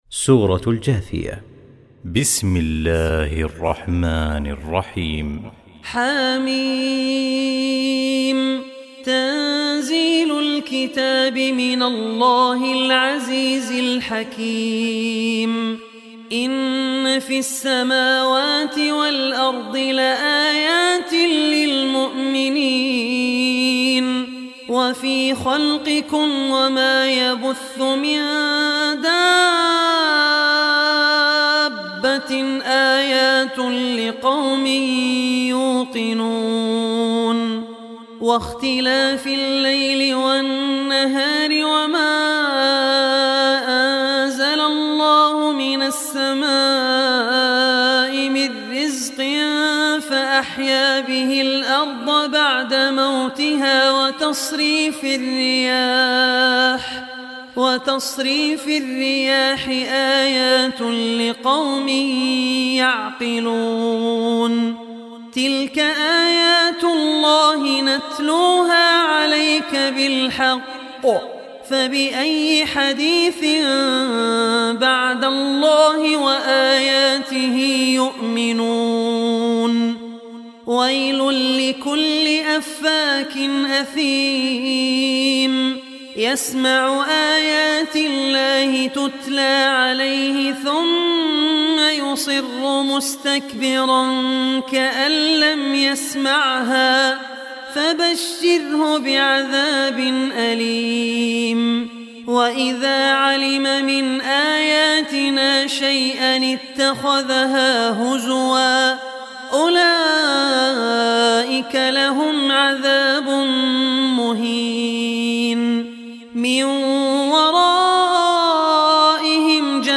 Surah al-Jathiya Recitation by Rahman Al Ausi
Surah al-Jathiya is 45 chapter or Surah of Holy Quran. Listen online mp3 tilawat / recitation of Surah al-Jathiya in the voice of Abdur Rahman Al Ossi.